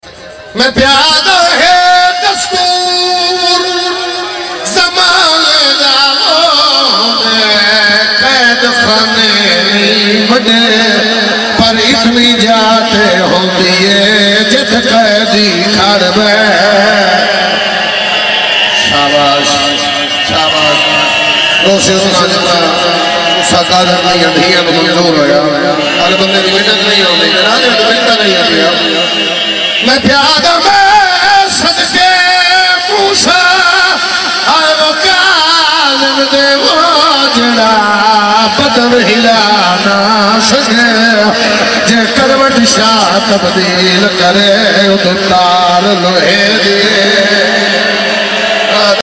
Majlis 2024